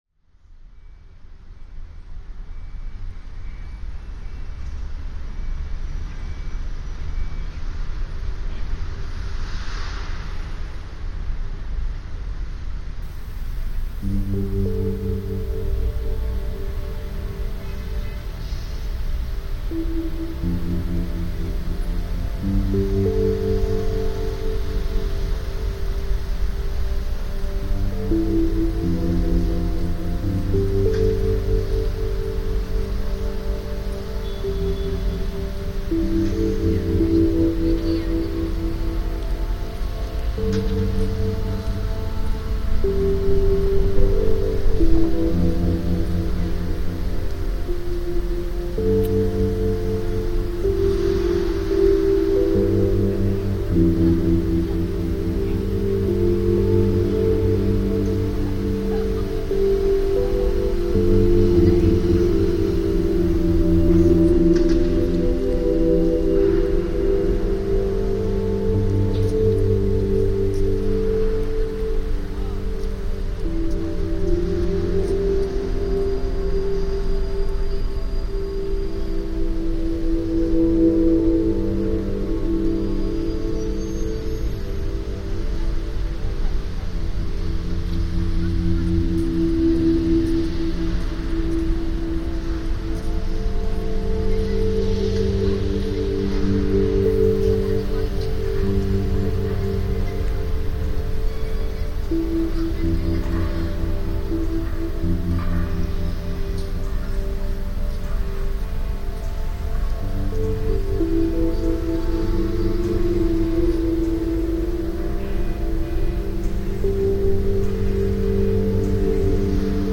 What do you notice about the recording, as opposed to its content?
London lockdown sound reimagined